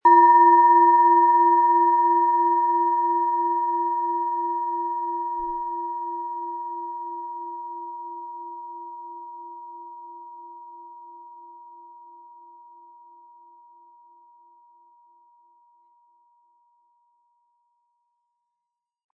Nach uralter Tradition von Hand getriebene Platonisches Jahr Planetenschale.
• Einsatzbereich: Über dem Kopf sehr intensiv spürbar. Ein unpersönlicher Ton.
Wenn Sie zum Klangbeispiel gehen, hören Sie den realen Ton der Schale.
SchalenformBihar
MaterialBronze